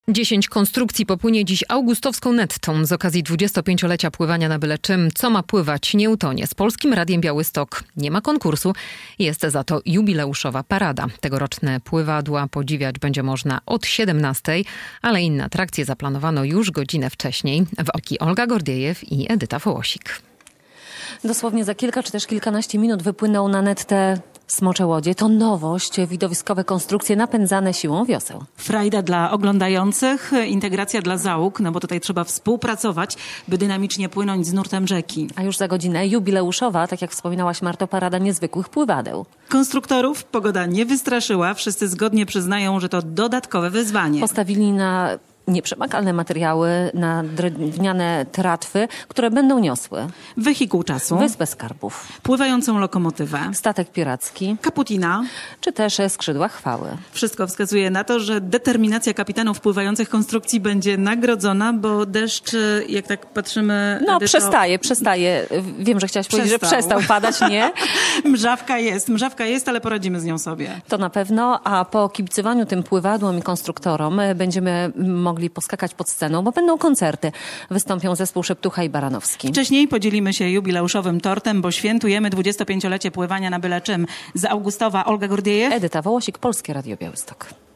Ostatnie chwile przed jubileuszową paradą pływadeł - relacja